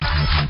energyDeflect.wav